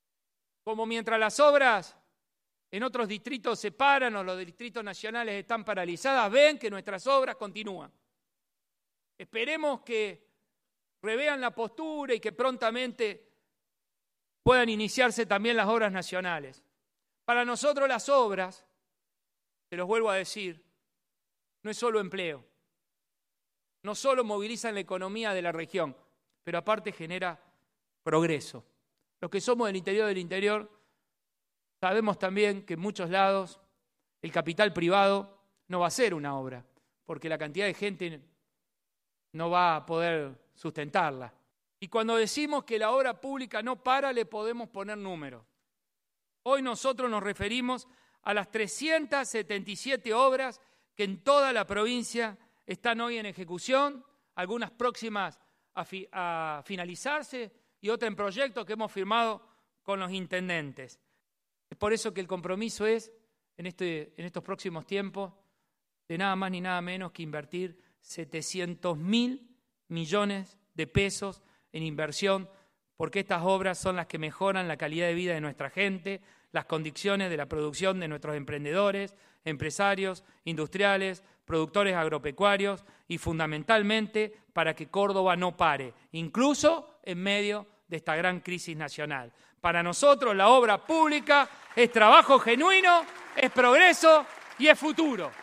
En el marco de su primera apertura de sesiones legislativas, el gobernador Martín Llaryora confirmó que su gestión mantendrá las inversiones destinadas a la obra pública en toda la provincia por más de $700.000 millones.
Audio: Gobernador Martín Llaryora